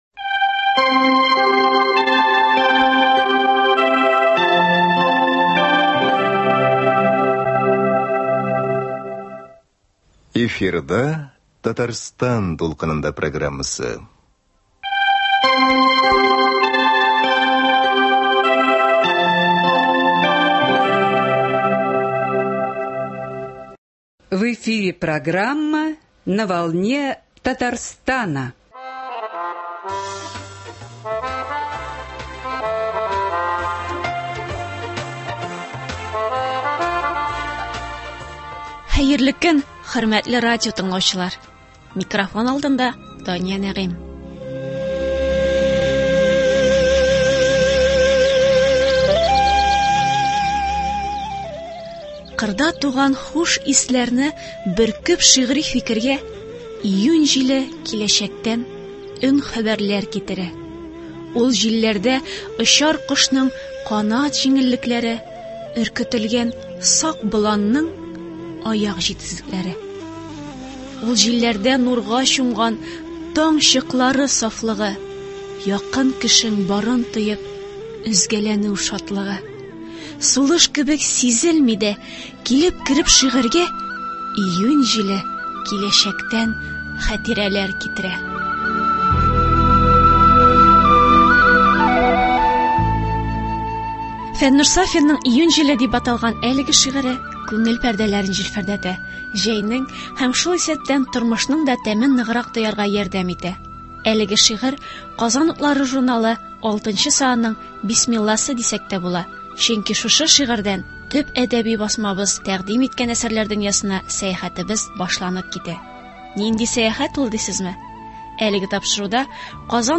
Әлеге тапшыруда “Казан утлары” журналының июнь санына күзәтү ясарбыз, яңа әсәрләр белән танышырбыз, авторлары белән әңгәмәләр корырбыз.